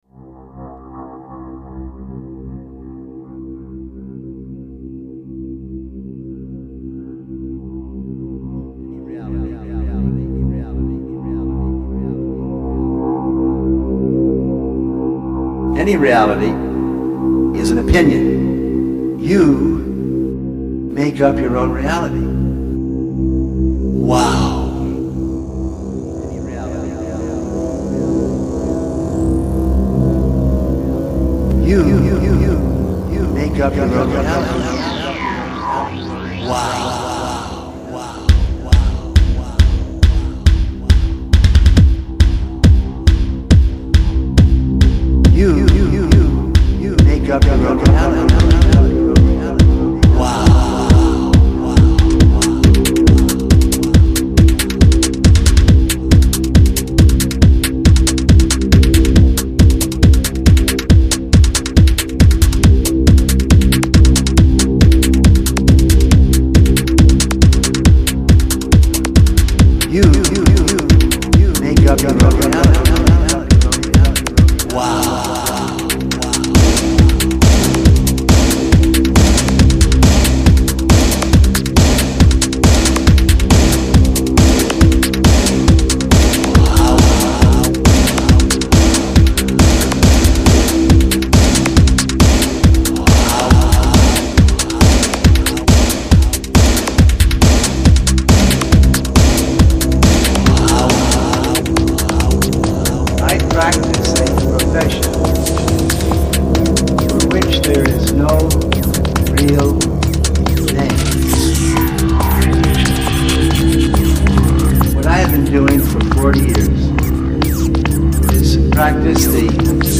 (2004, pretty much an EBM track, all by me)